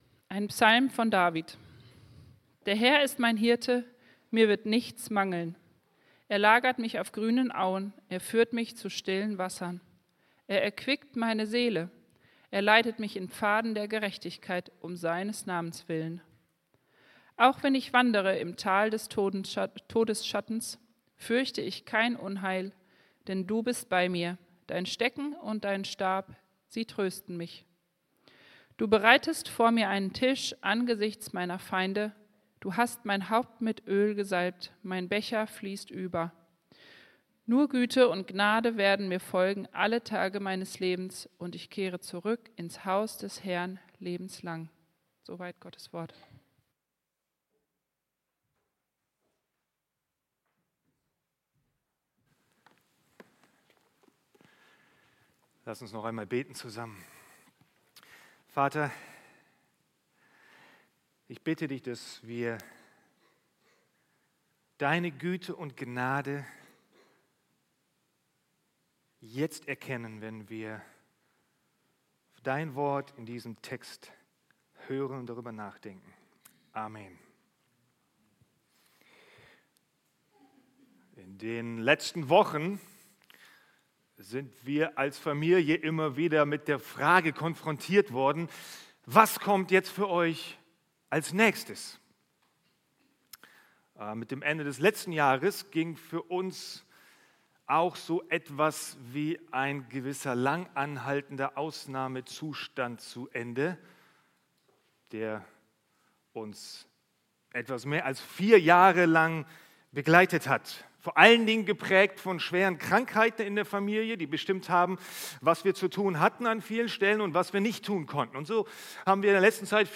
Serie: Einzelne Predigten